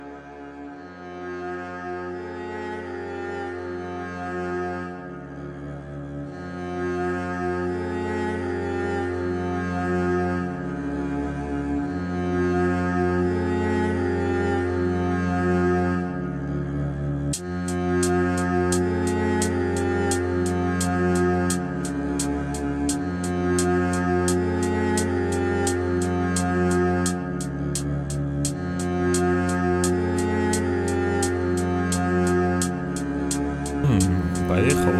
Жанр: Русские
# Хип-хоп